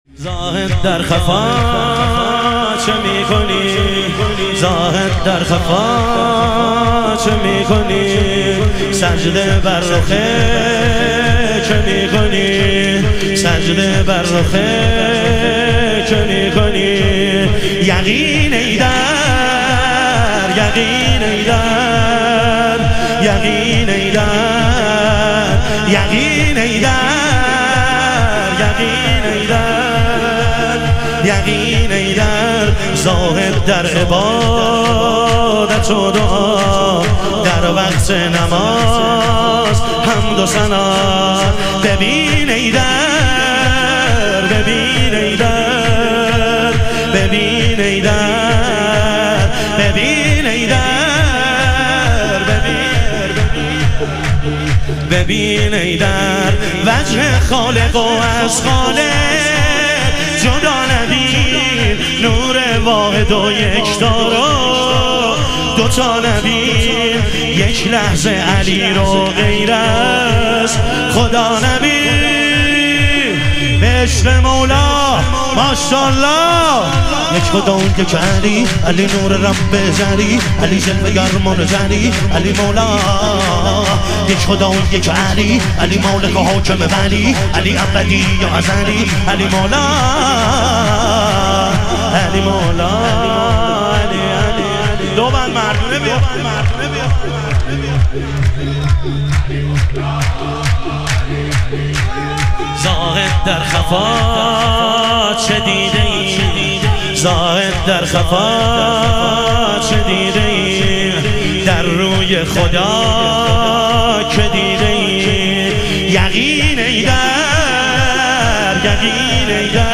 ظهور وجود مقدس حضرت امیرالمومنین علیه السلام - شور